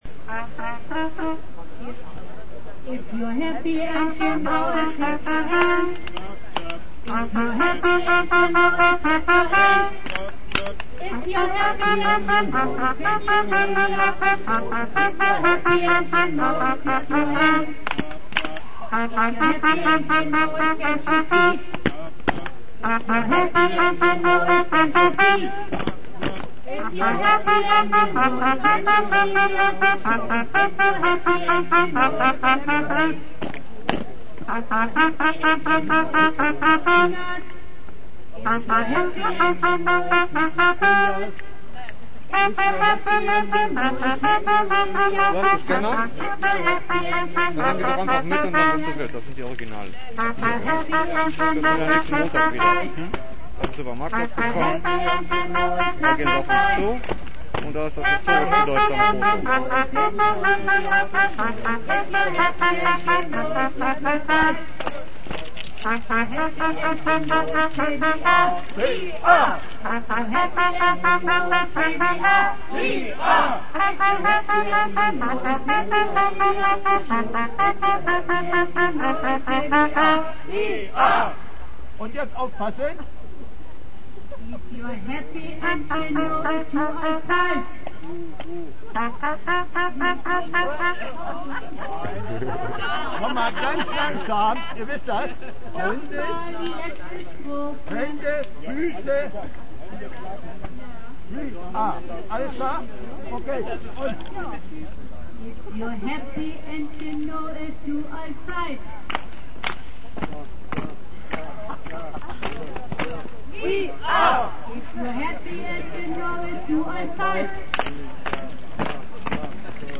150. Bremer Montagsdemo